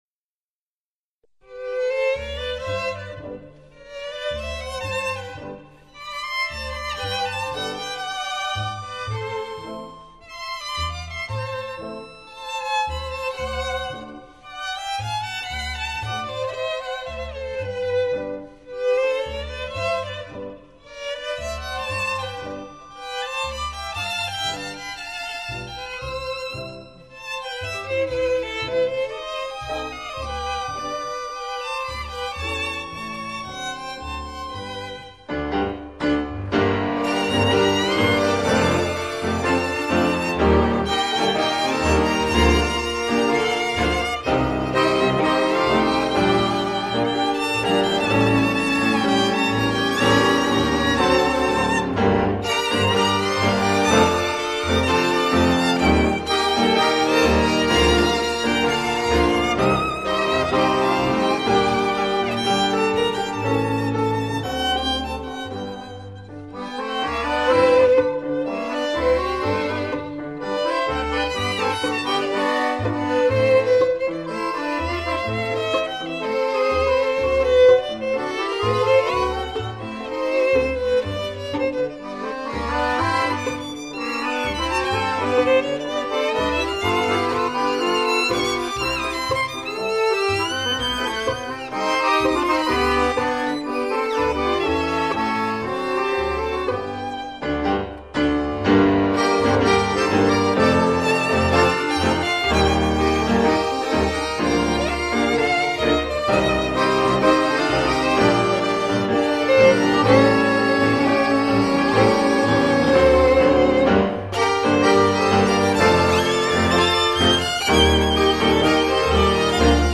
进入B段后的激情将舞者与观众的情绪推到最高点。然后突然做减慢，回 到首调收尾。